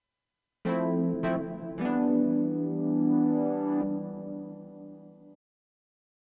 очень слышно писк и шум при увеличении входного уровня, как победить?) apollo twin duo tb, стационарный пк